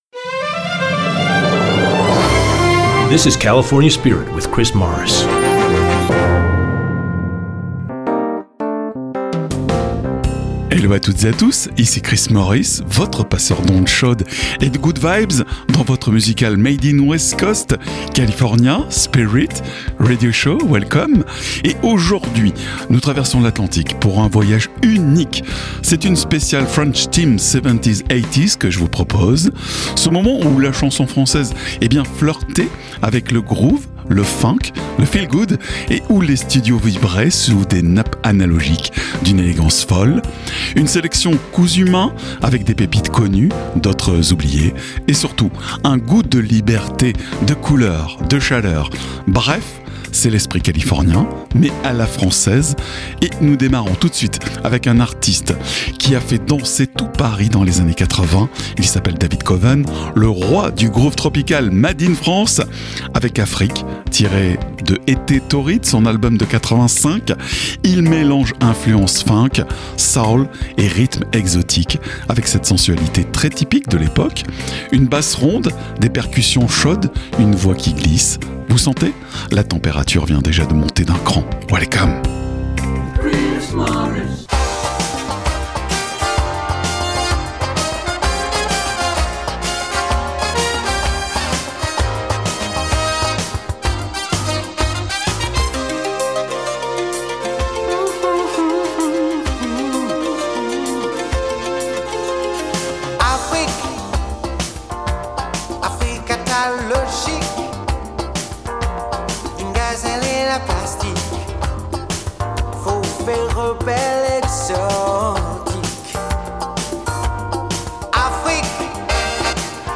Le California Spirit est un concept unique inspiré des radios US , avec des PowersPlays (nouveautés) et ExtraGold (Oldies).
C’est un format musique californienne (Allant du Classic Rock en passant par le Folk, Jazz Rock, Smooth jazz) le tout avec un habillage visuel très 70’s et un habillage sonore Made in America.